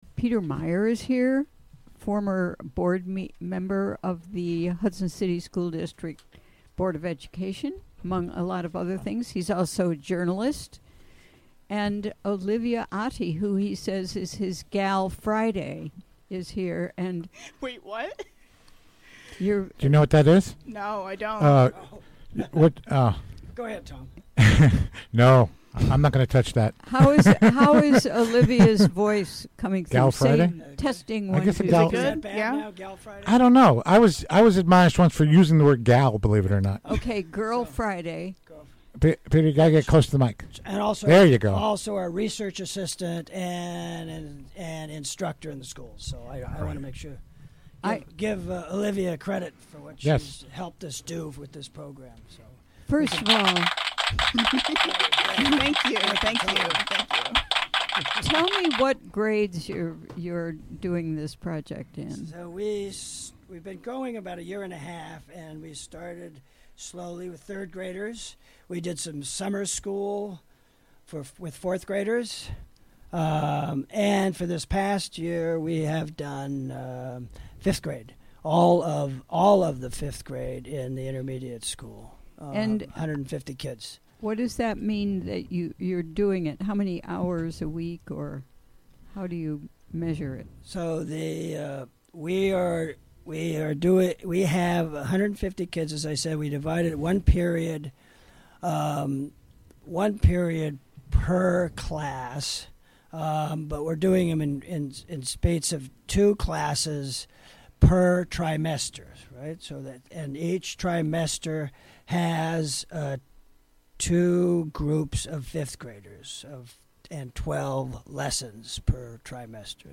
Recorded live during the WGXC Afternoon Show Thursday, May 10, 2018.